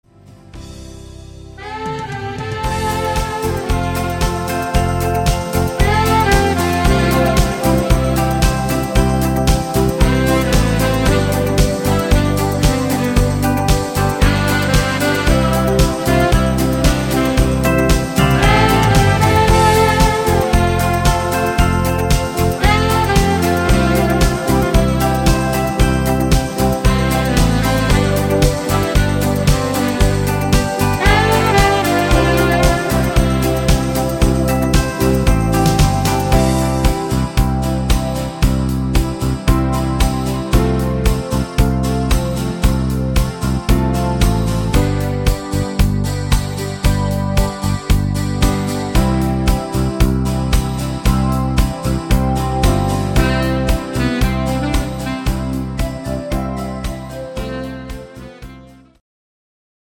Instumental Sax